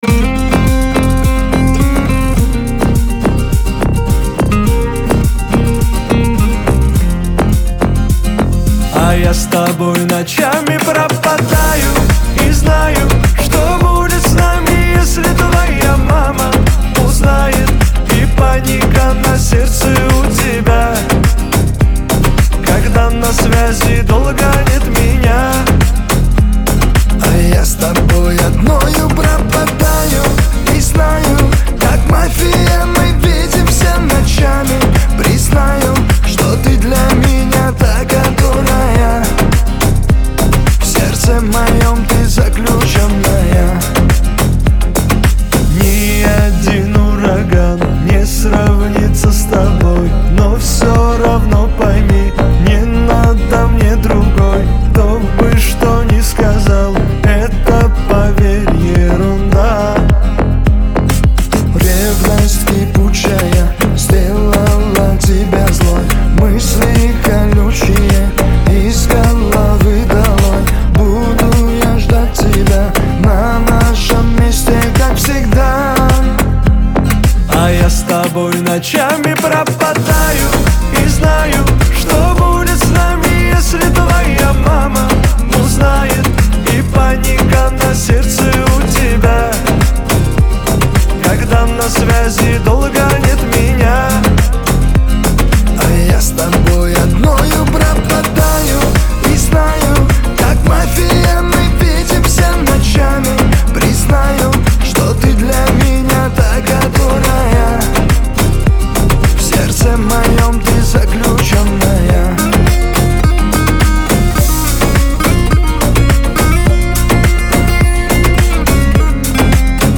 Танцевальная музыка
весёлые песни , песни для танцев